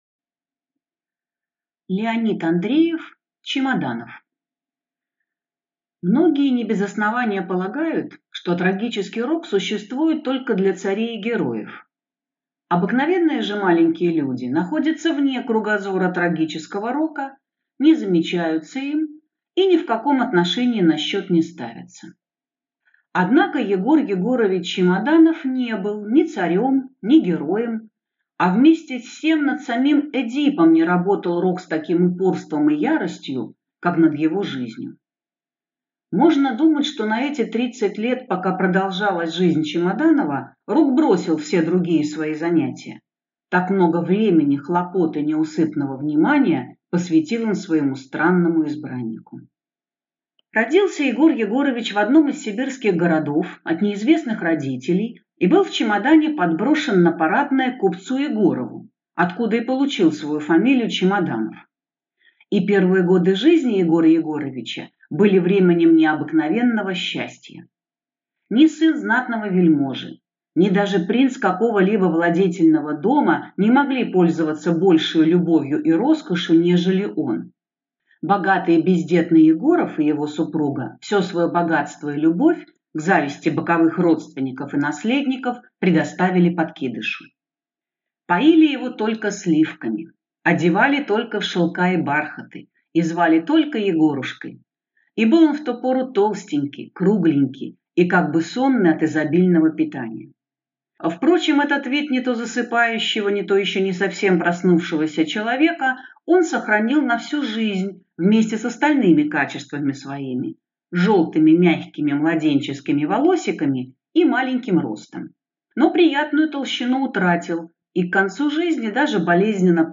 Аудиокнига Чемоданов | Библиотека аудиокниг